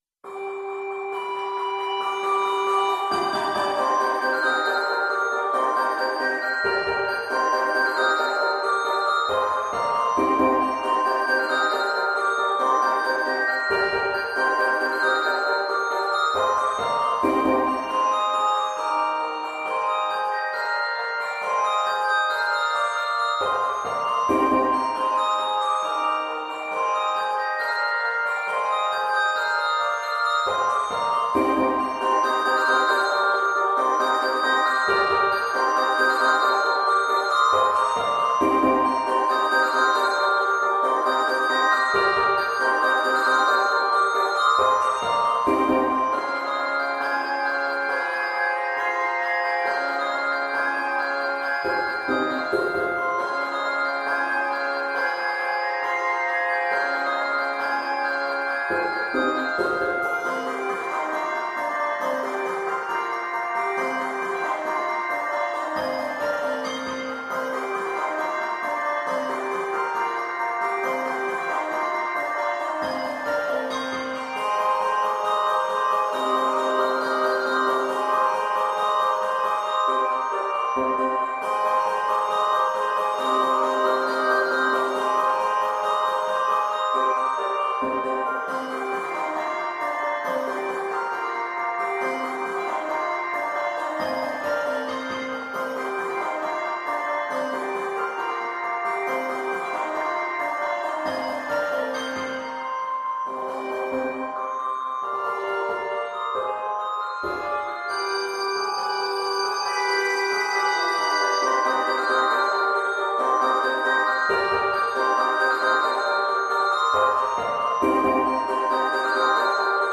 Its tempo is quite challenging– half note = 96-132!